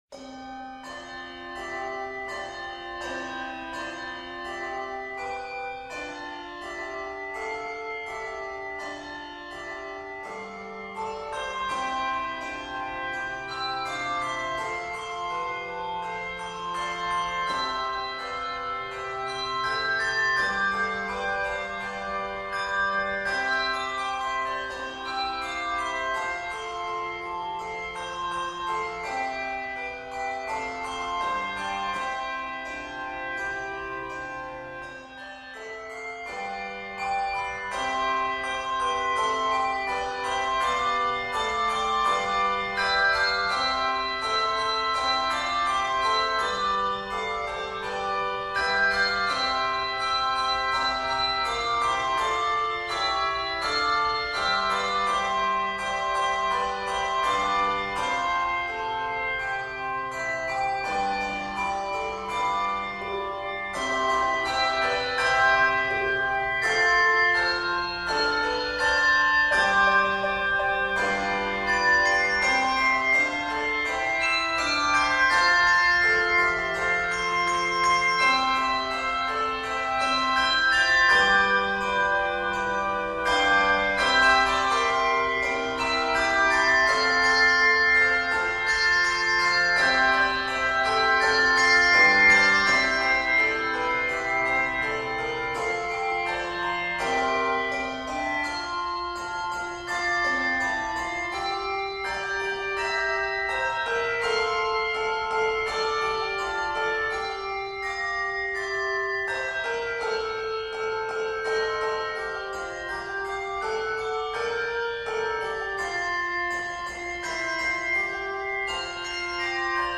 expressive arrangement